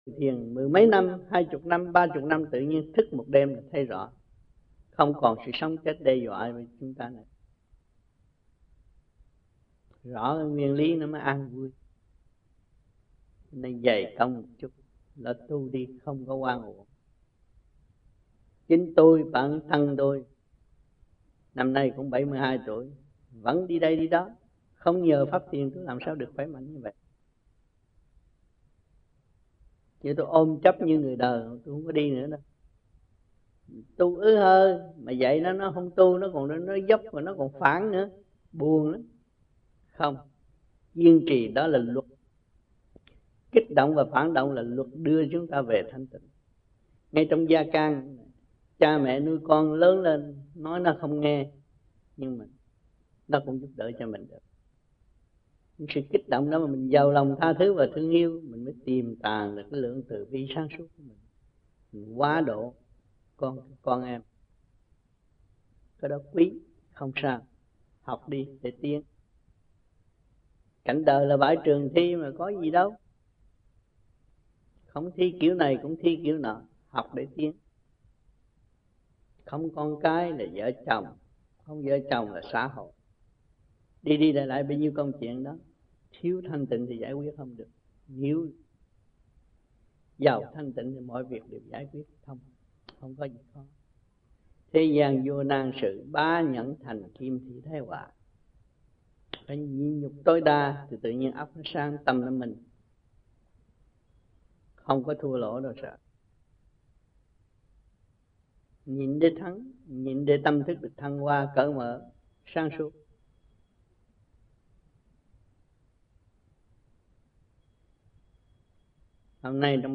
1994-01-14 - Vancouver - Thuyết Pháp